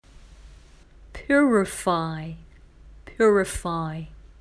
purifypjˈʊ(ə)rəfὰɪ
⇒ 発音見本は